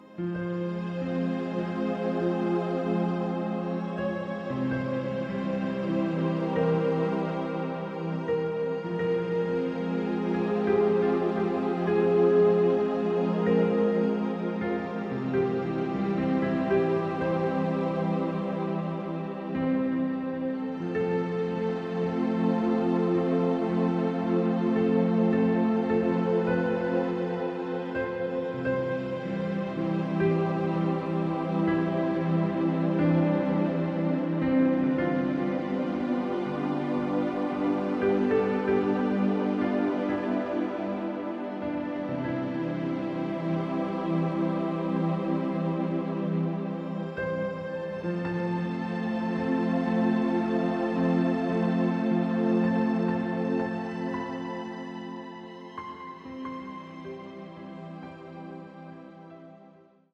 Music to Enhance Your own personal Home Spa Experience